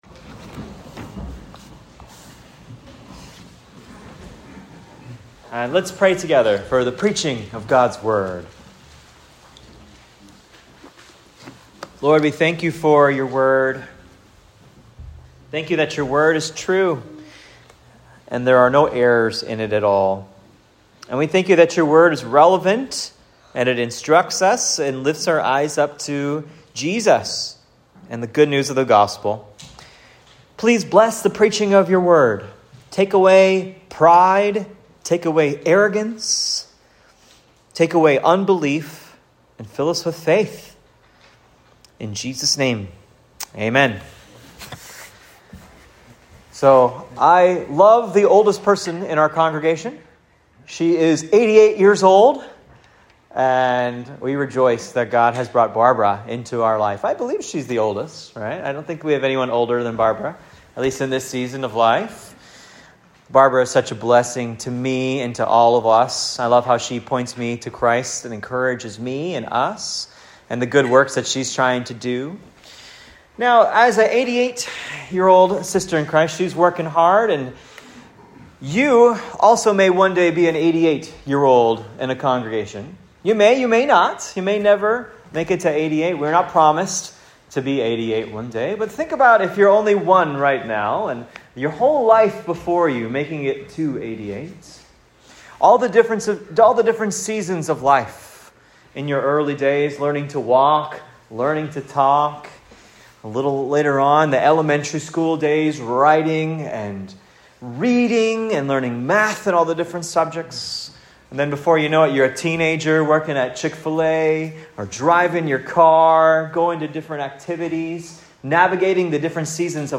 GOSPEL For ALL of Life. Ephesians 6:1-9 SERMON